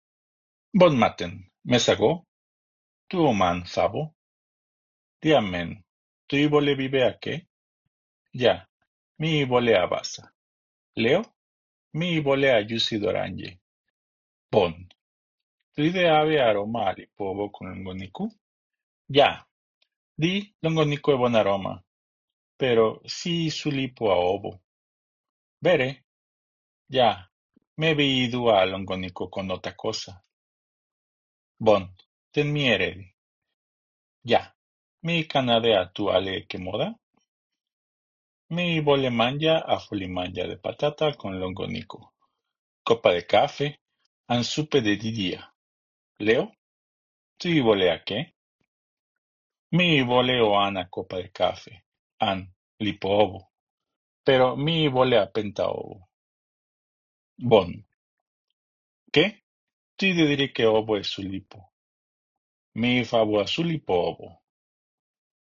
Finally, a conversation: